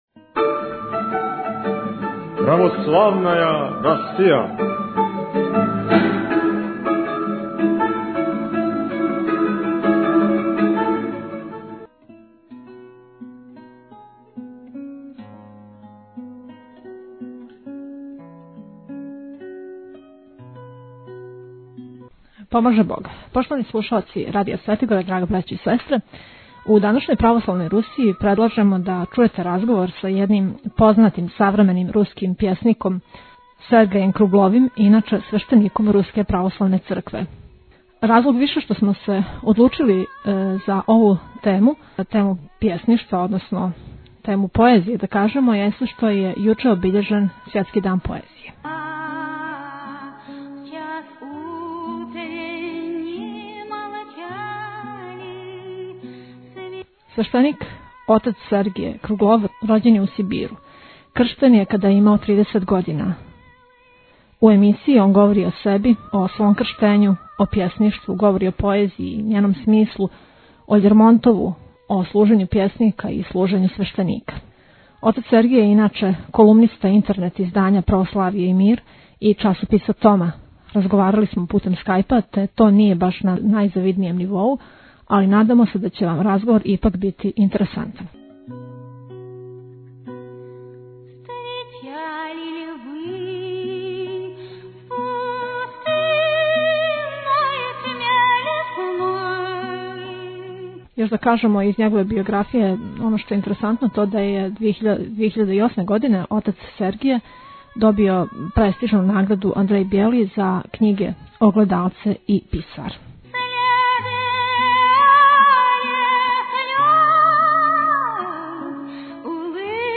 Такође ћете чути његове стихове.